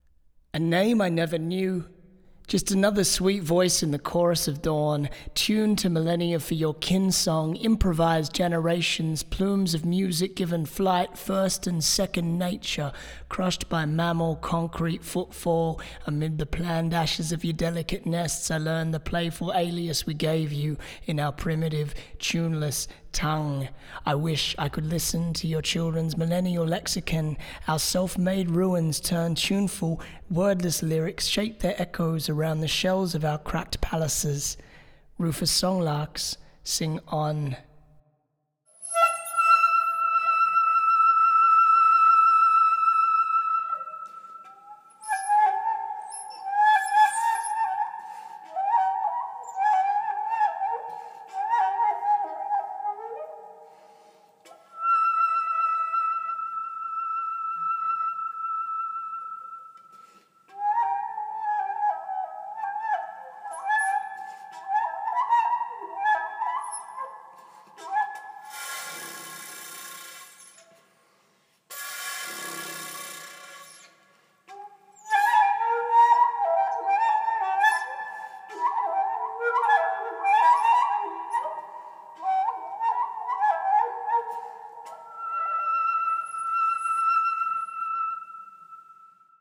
Rufous Songlark
bird call by musician Brian Ritchie on Shakuhachi.